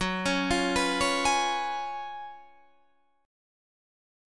GbM7sus2sus4 chord